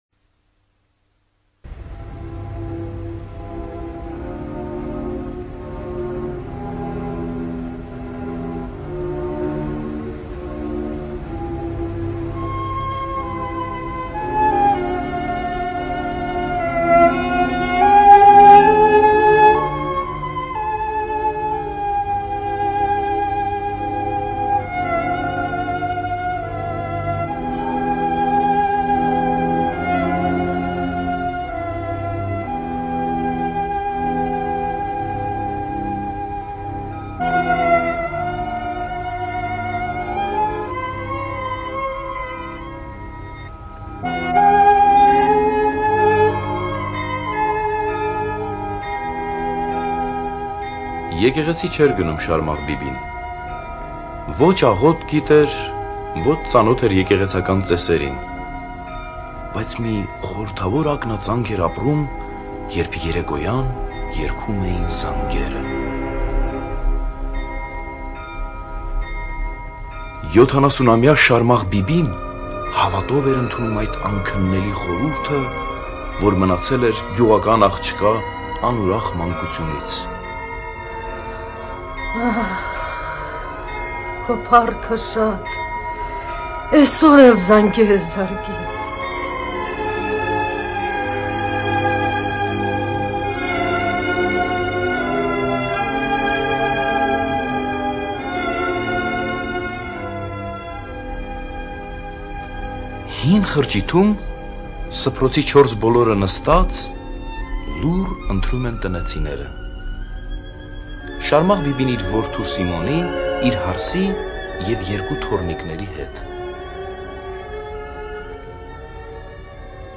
ЖанрРадиоспектакли на армянском языке